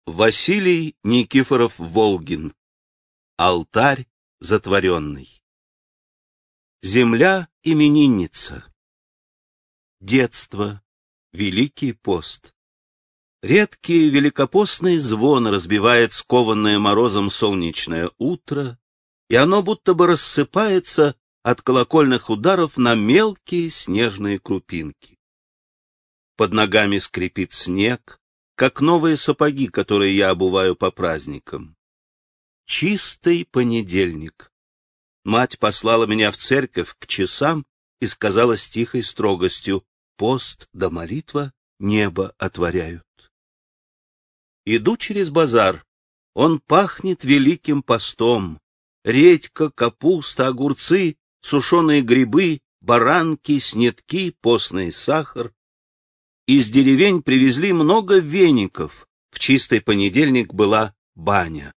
Аудиокнига Алтарь затворенный | Библиотека аудиокниг